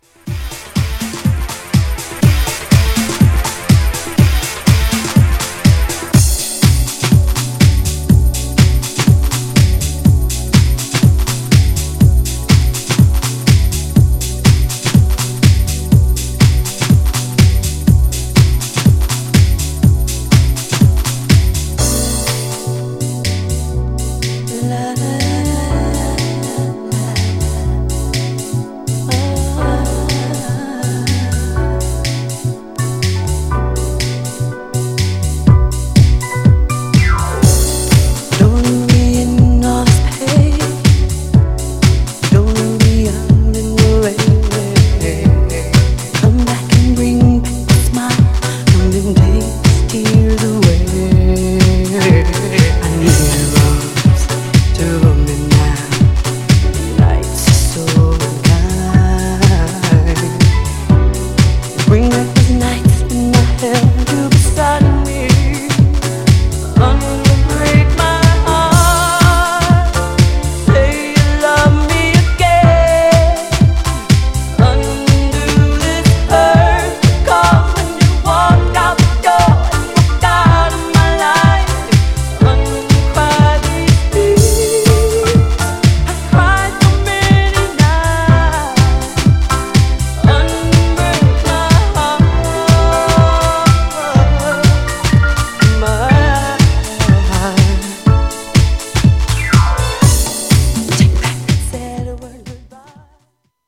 オリジナルより断然メロウ!!
GENRE R&B
BPM 76〜80BPM